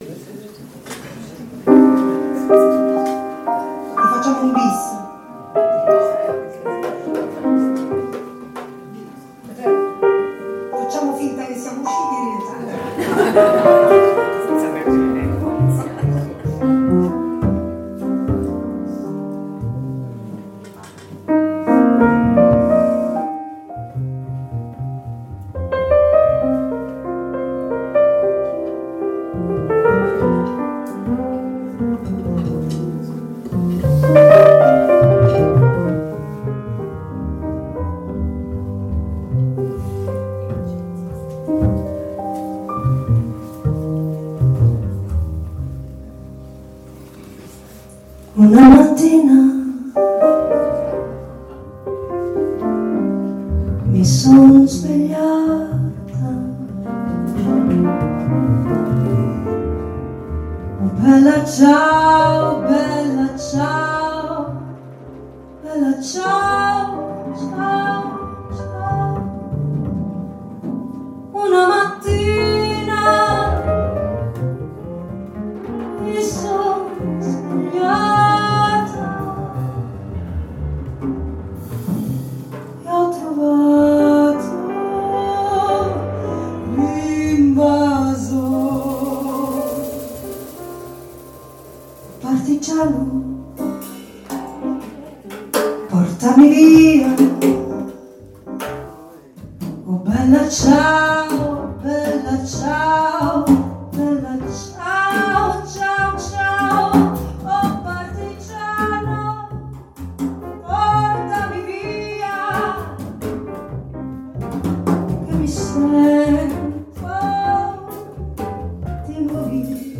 Sala Consiliare
Batteria
Contrabbasso
Pianoforte
Voce